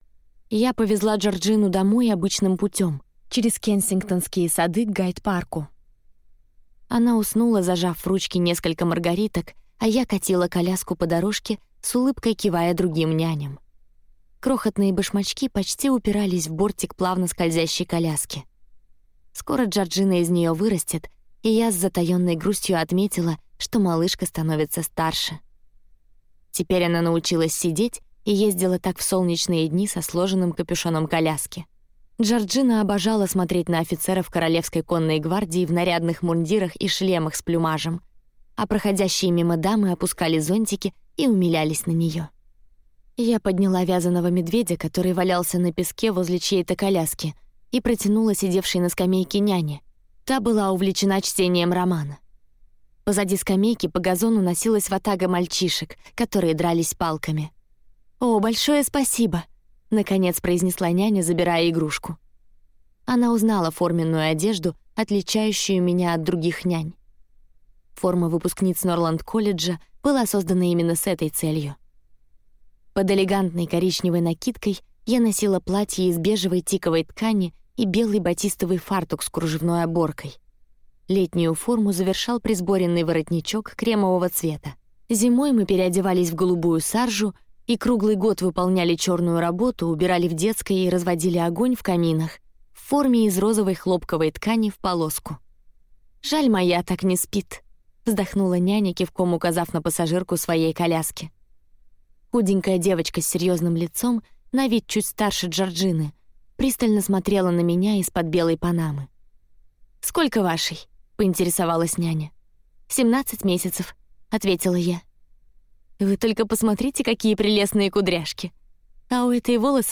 Аудиокнига Миссис Ингланд | Библиотека аудиокниг
Прослушать и бесплатно скачать фрагмент аудиокниги